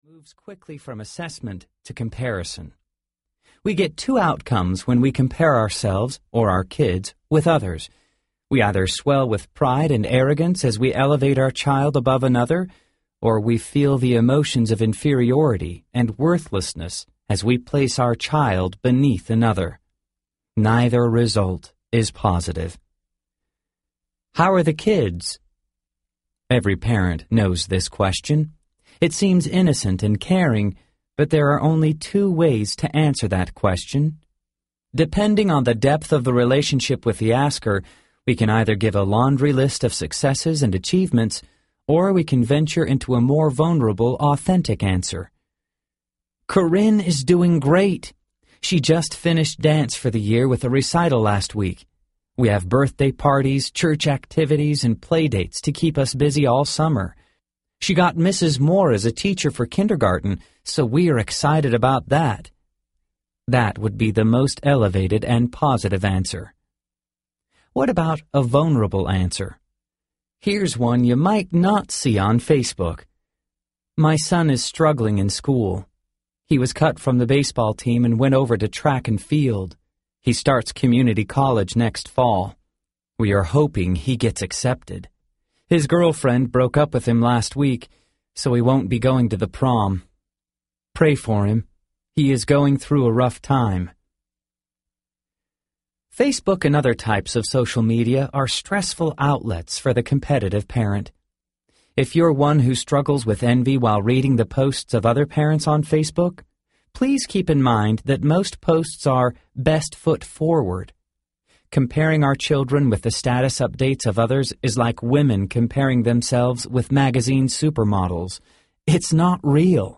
Trophy Child Audiobook